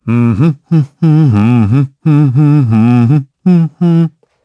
Siegfried-Vox_Hum.wav